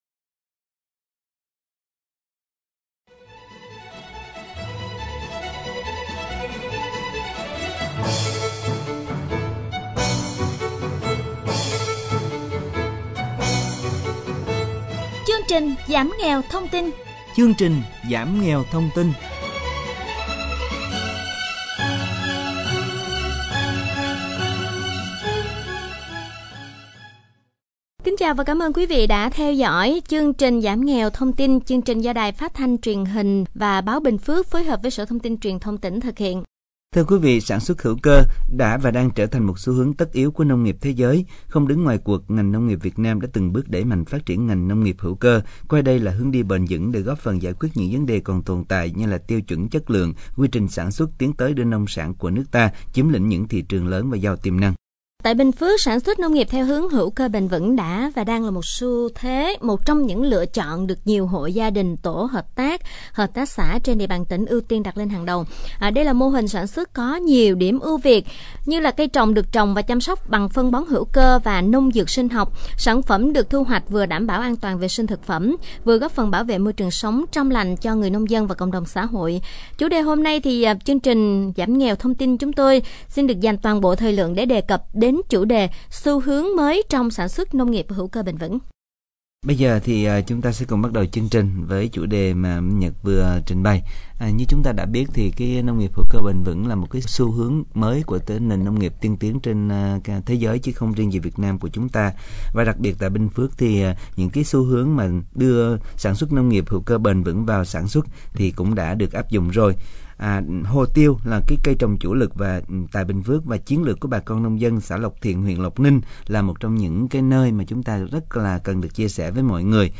File phát thanh tuyên truyền giảm nghèo thông tin năm 2019